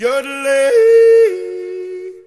Jodler Download
jodler.mp3